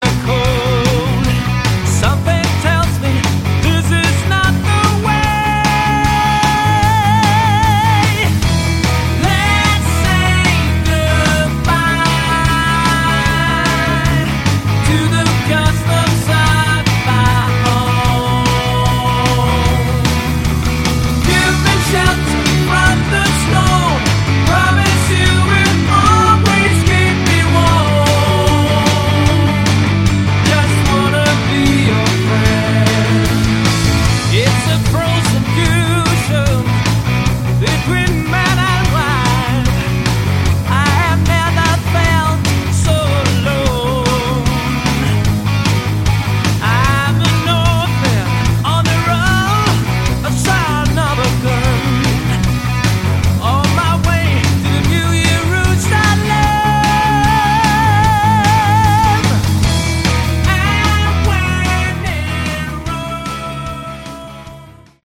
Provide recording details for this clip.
Rough Mix